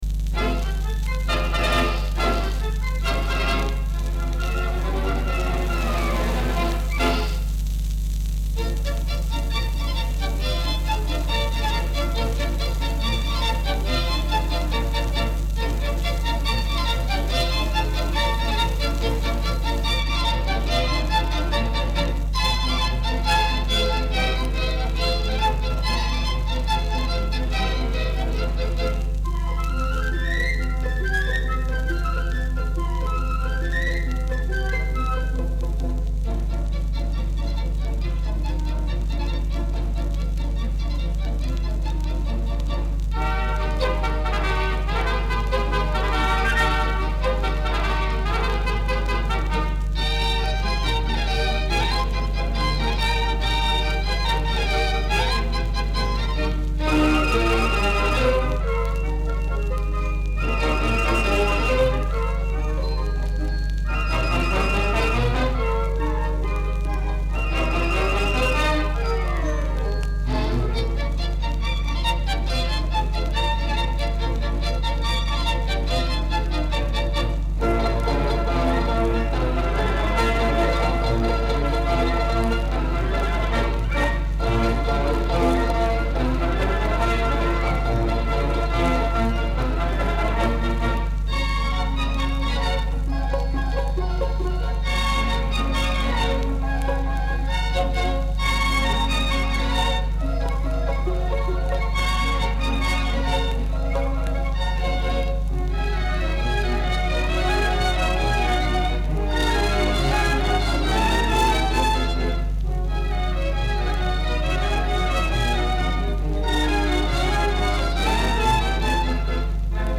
Описание: Веселая и жизнерадостная мелодия